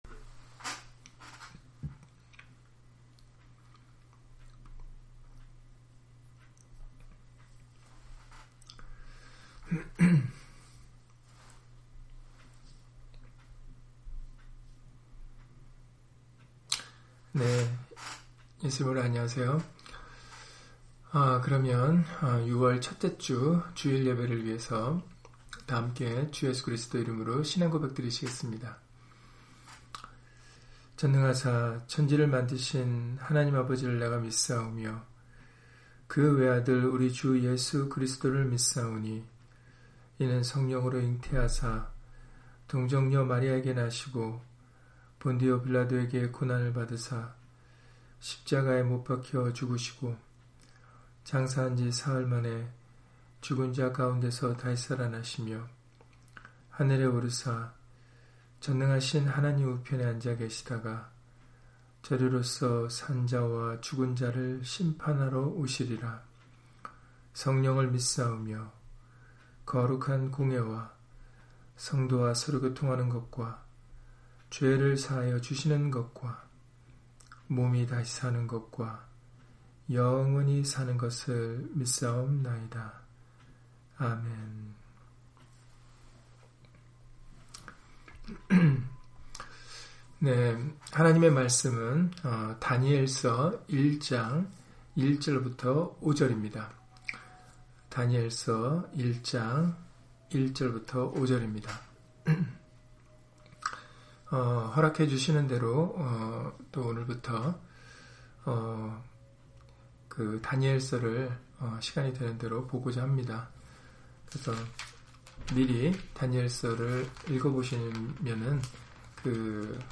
다니엘 1장 1-5절 [다니엘서의 시작] - 주일/수요예배 설교 - 주 예수 그리스도 이름 예배당